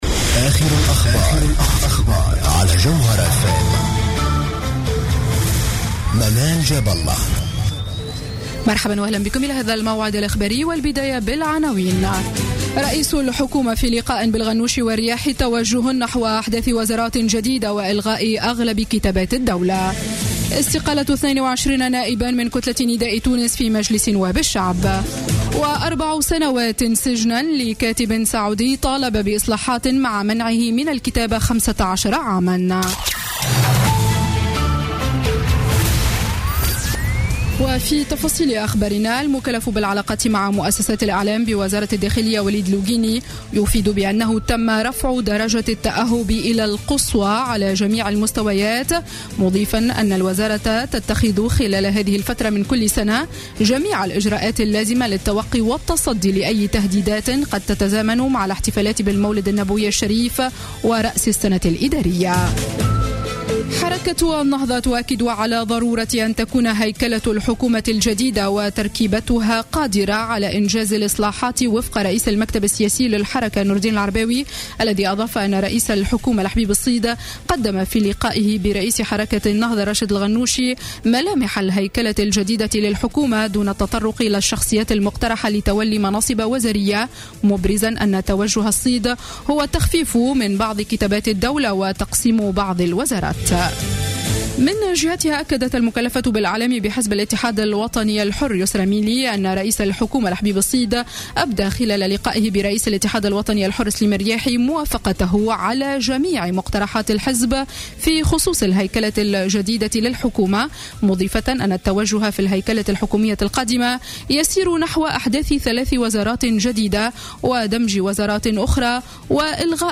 نشرة أخبار السابعة مساء ليوم الاثنين 21 ديسمبر 2015